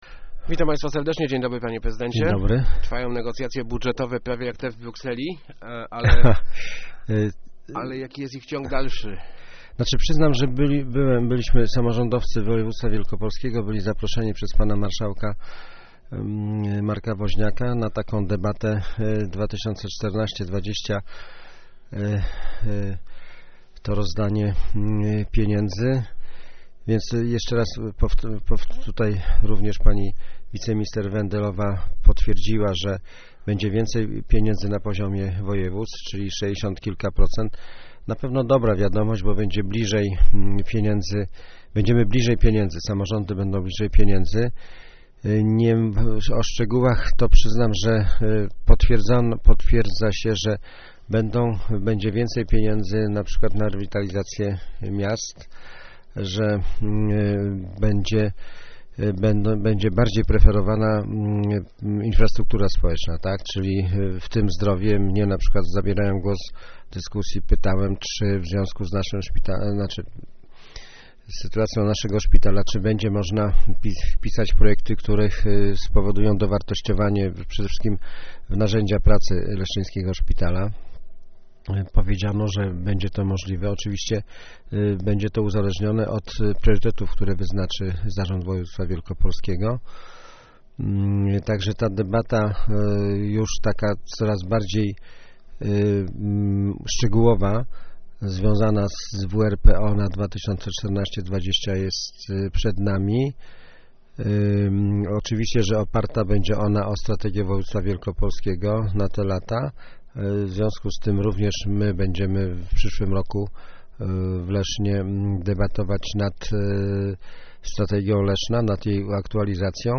Gościem Kwadransa jest prezydent Tomasz Malepszy.